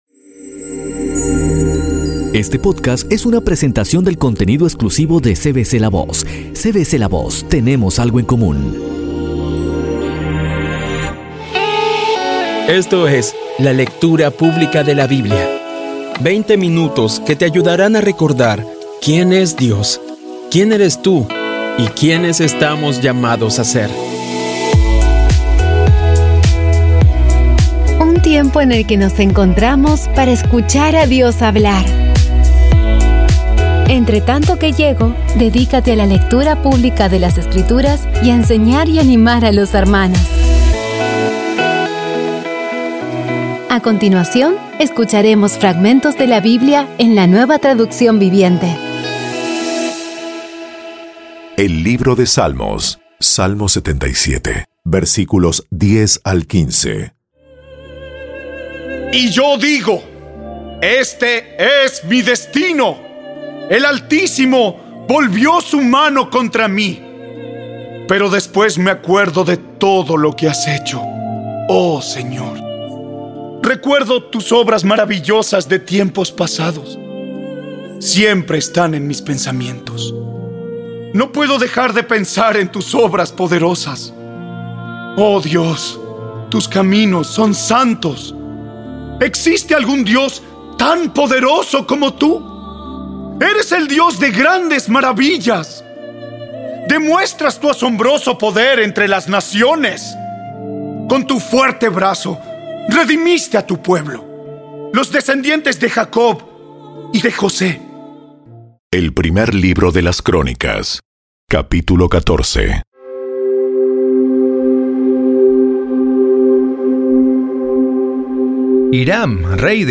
Audio Biblia Dramatizada Episodio 180
Poco a poco y con las maravillosas voces actuadas de los protagonistas vas degustando las palabras de esa guía que Dios nos dio.